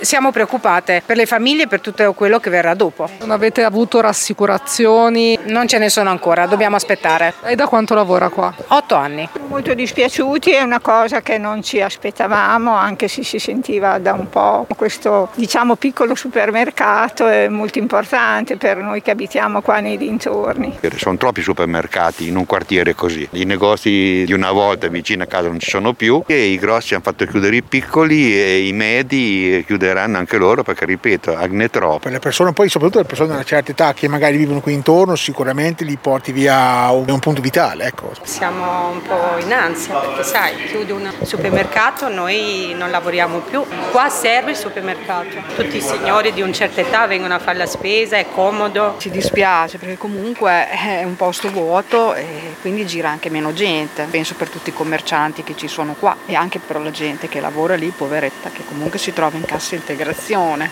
Qui sotto le interviste